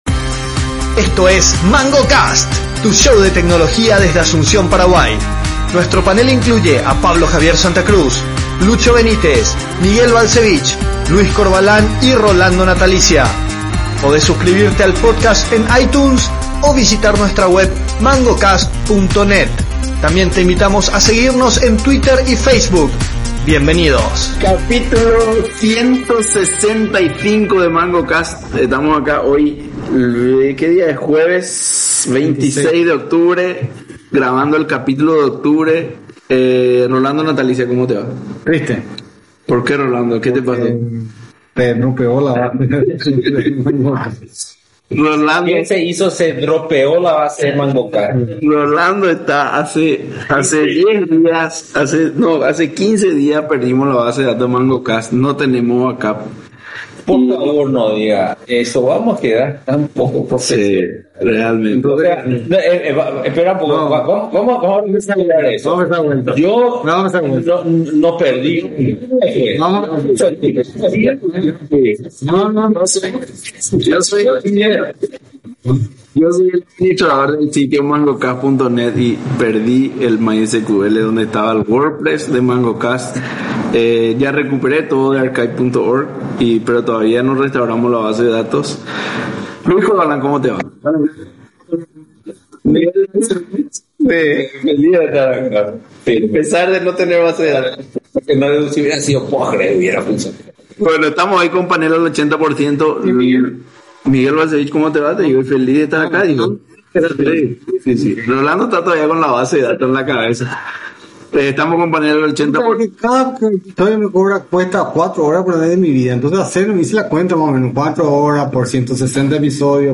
Como siempre, se genera un diálogo animado y controversial: desde la autosuba de salarios de gobernantes, hasta permitir que se saquen perros a la calle para 💩.
Temas serios, pero siempre salpicados con el característico tono humorístico y acompañado de coro de risas.